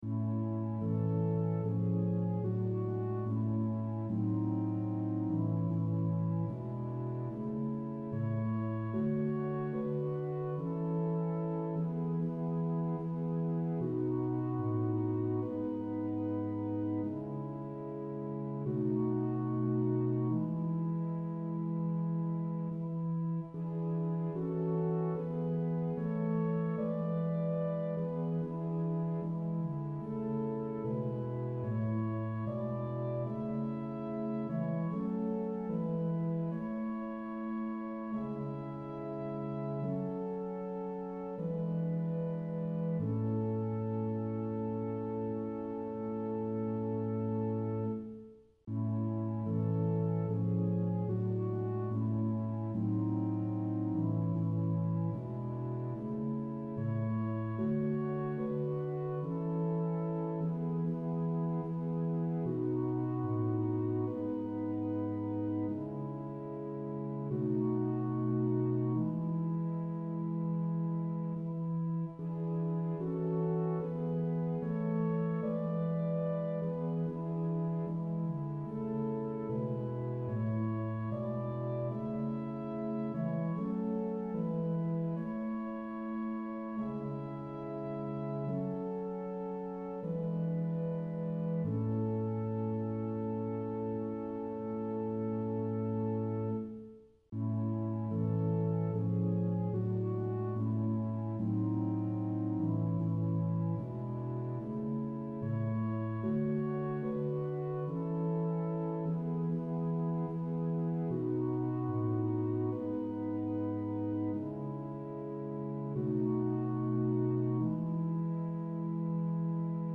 Click the Button to sing the prayer in A, or play the song in a New Window